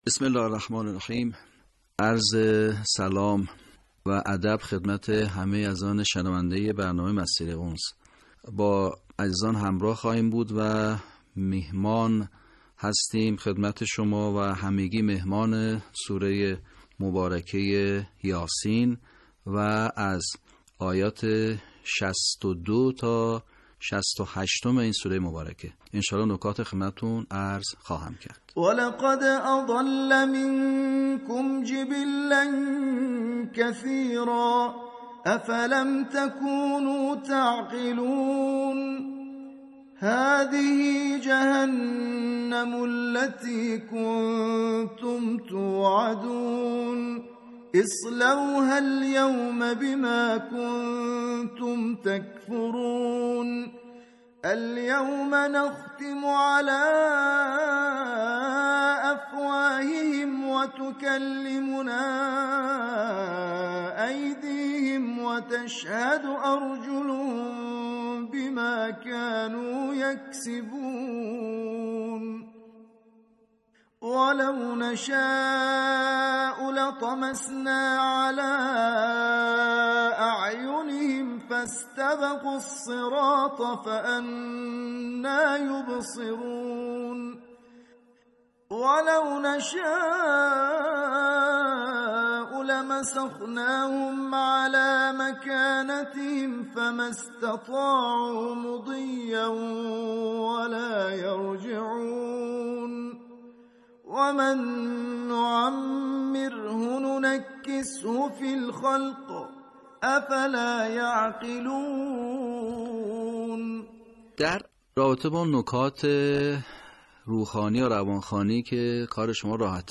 صوت | صحیح‌خوانی آیات ۶۲ تا ۶۸ سوره یس